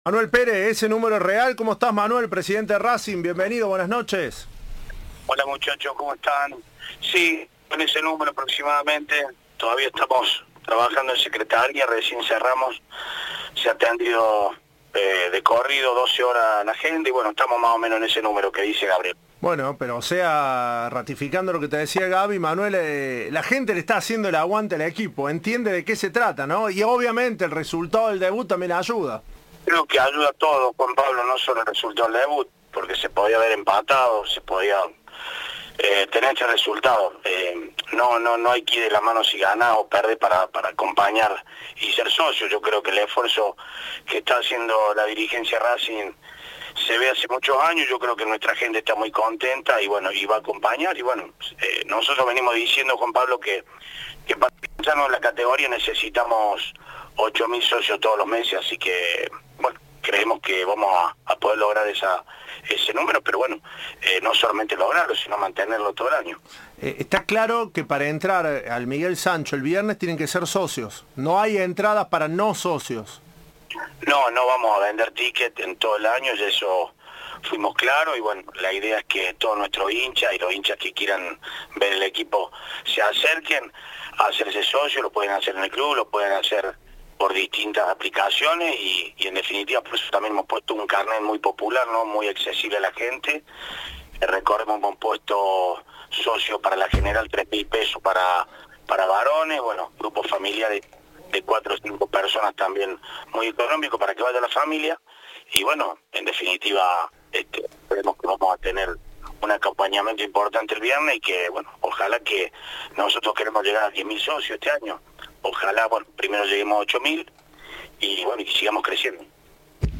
Entrevista de "Tiempo de Juego"